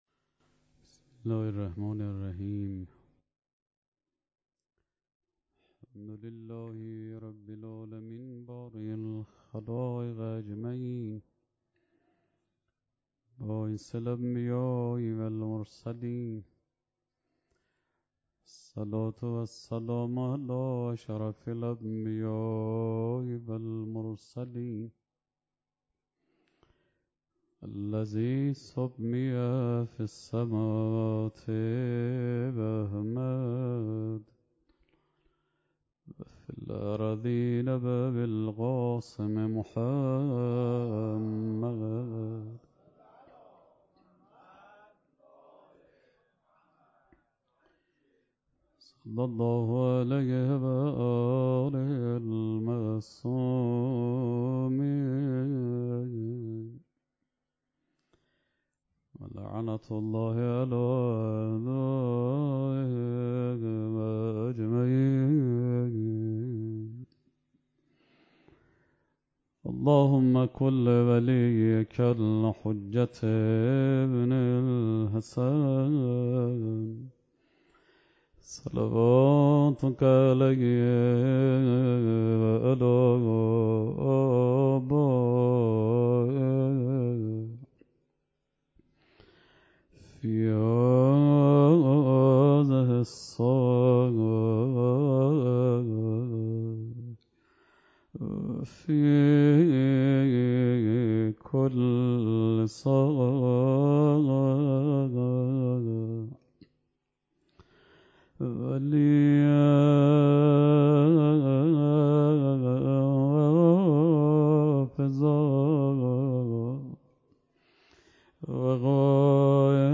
شب شانزدهم ماه مبارک رمضان 97 - بیت الرقیه - نگرانی های پیامبر صلوات الله علیه وآله بر امت